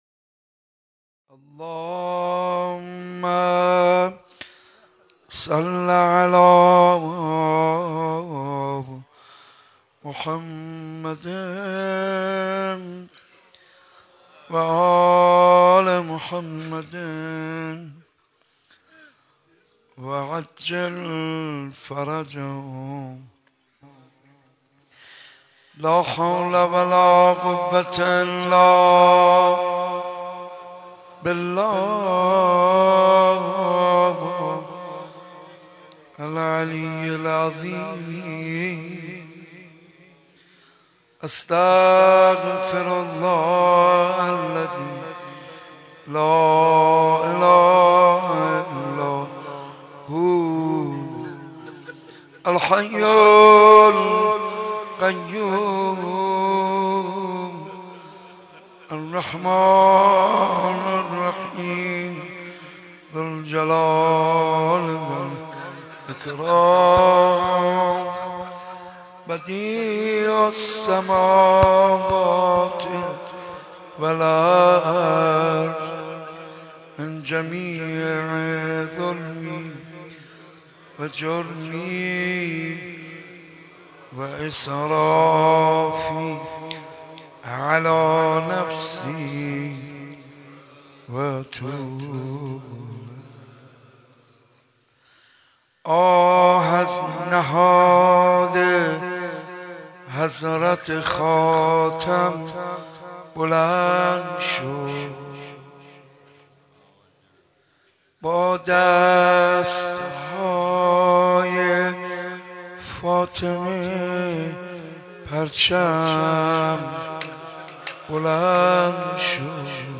حاج منصور ارضی مداح
مناسبت : شب ششم محرم
قالب : مجلس کامل